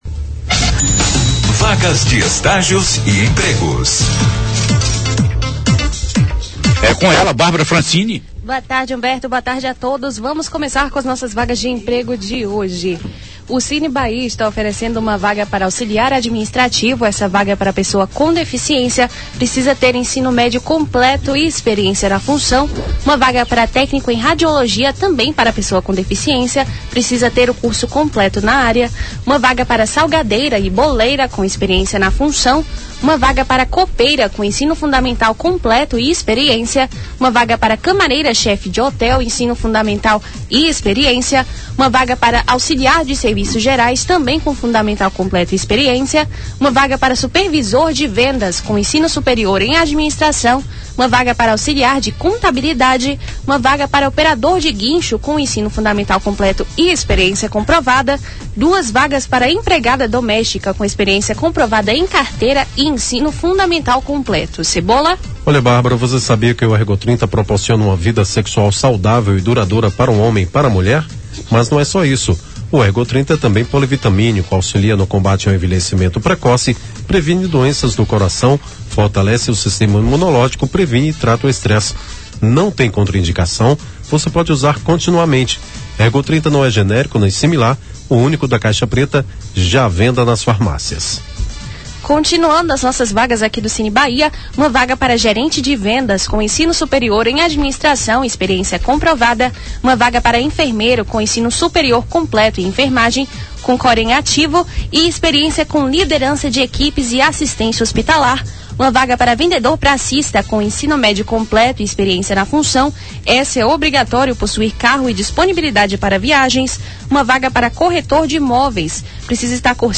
Na sonora, a repórter traz todos os destaques de empregos e estágios em Vitória da Conquista.